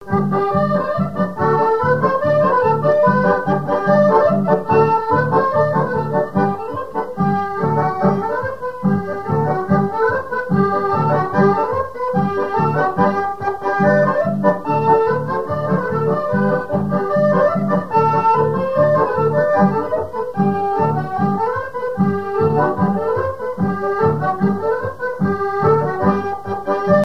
Couplets à danser
branle : courante, maraîchine
répertoire d'airs à danser
Pièce musicale inédite